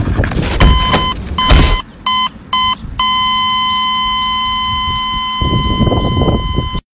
Death Sound Effects Free Download